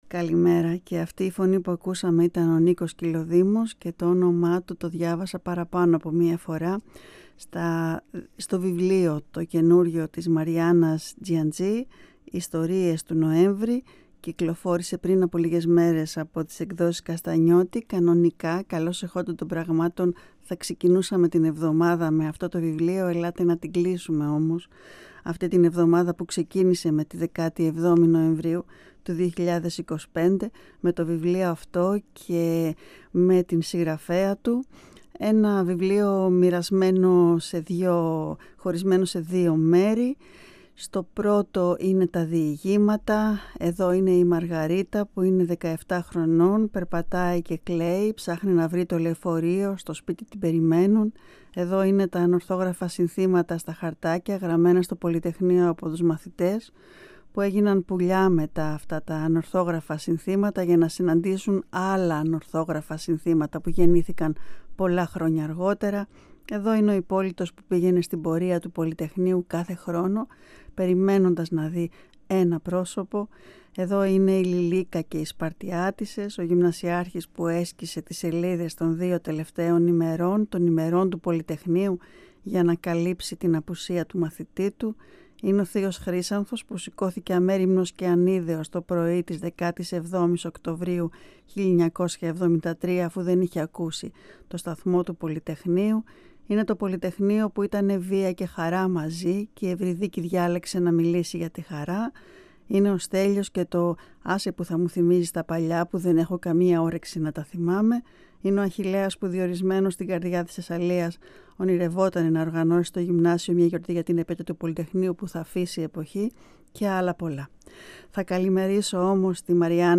Συνέντευξη με την Μαριάννα Τζιαντζή για το βιβλίο της “Ιστορίες του Νοέμβρη” (Εκδόσεις Καστανιώτη).